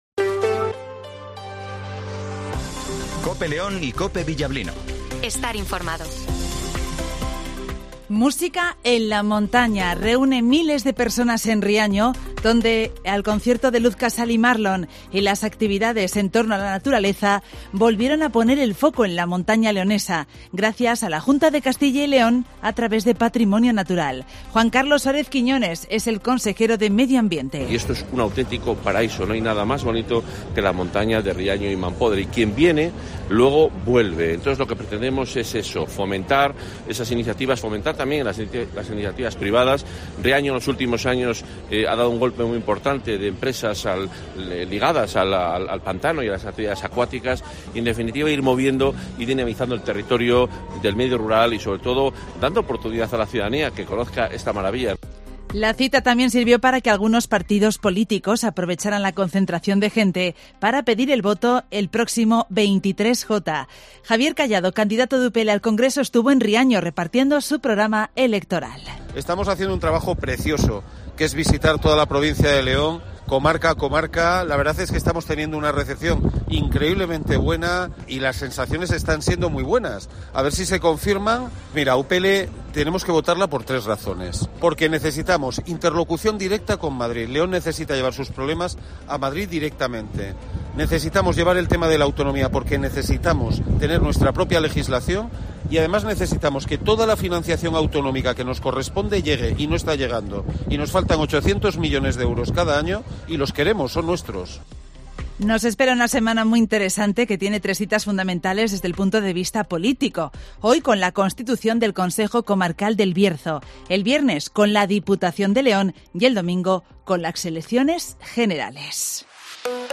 - Informativo Matinal 08:25 h